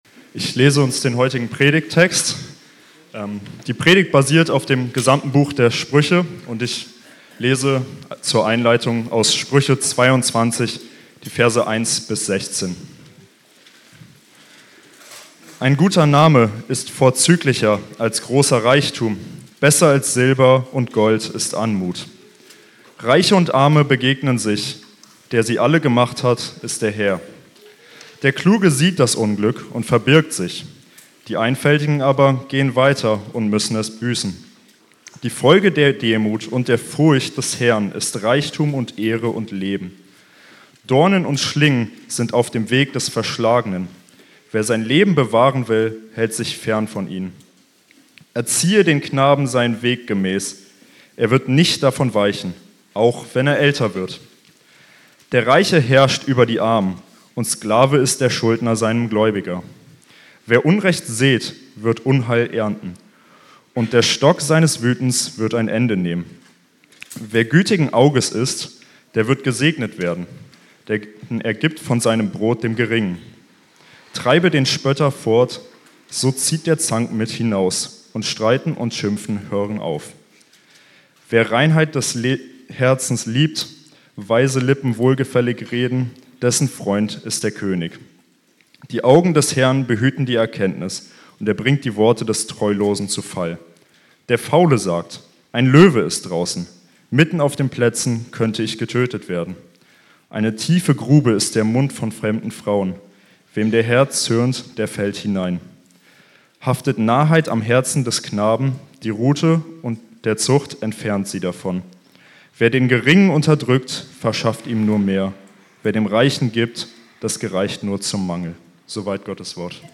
Serie: Einzelne Predigten